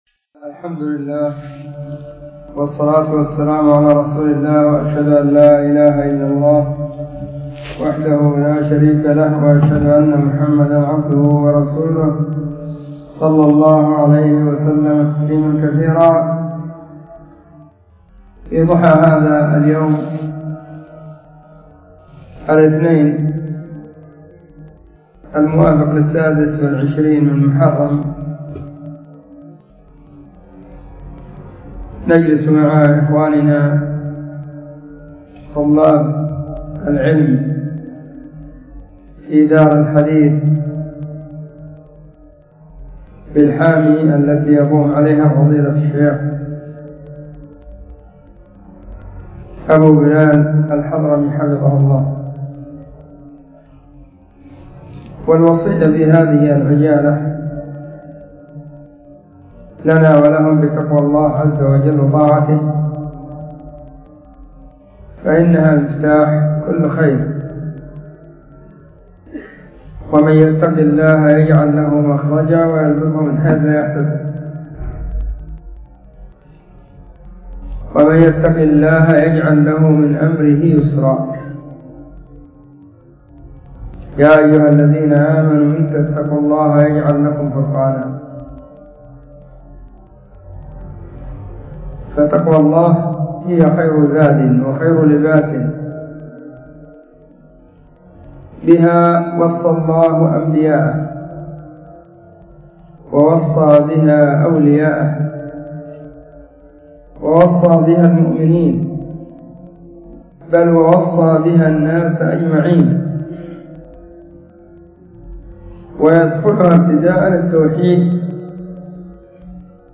محاضرة بعنوان;الوصية بتقوى الله عزوجل.
📢 مسجد الصحابة – بالغيضة – المهرة، اليمن حرسها •اللّـہ̣̥.